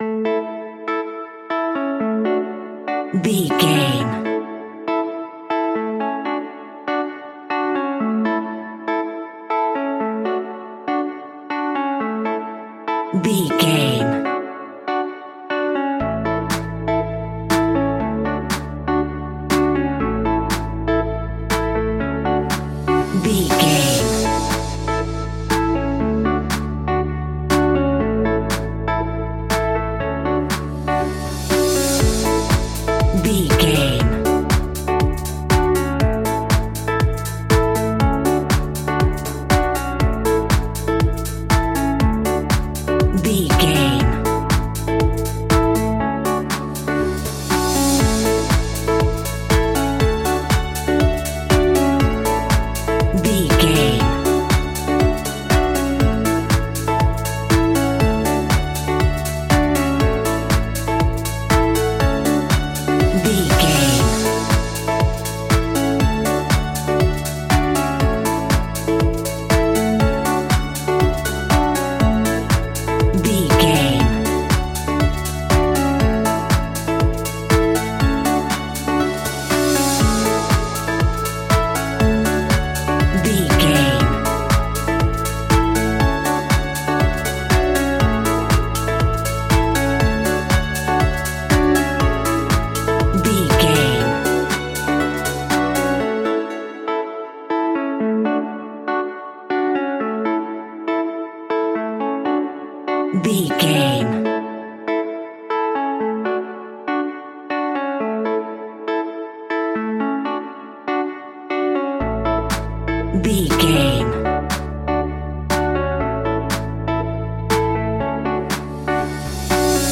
Ionian/Major
energetic
uplifting
hypnotic
electric guitar
bass guitar
drum machine
synthesiser
funky house
disco
instrumentals